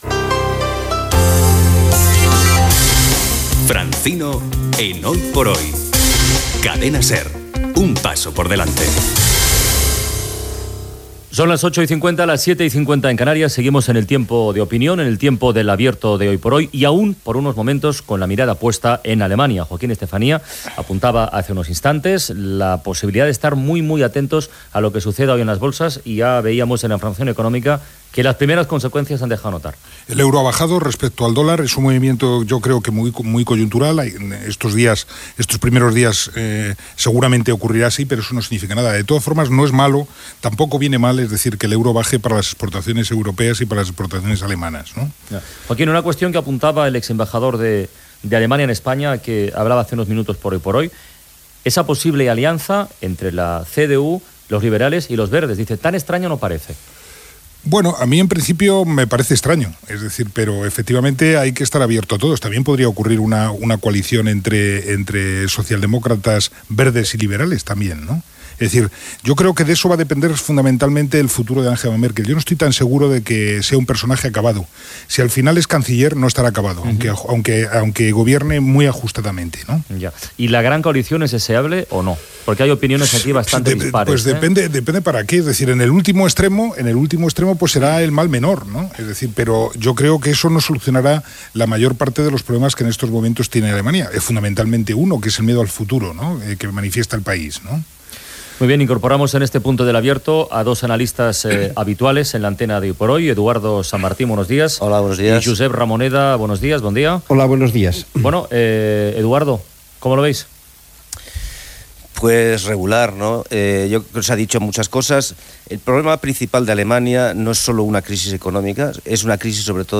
Primer programa de Carles Francino. Indicatiu del programa, hora, tertúlia "El abierto" valorant el resultat de les eleccions alemanyes
Info-entreteniment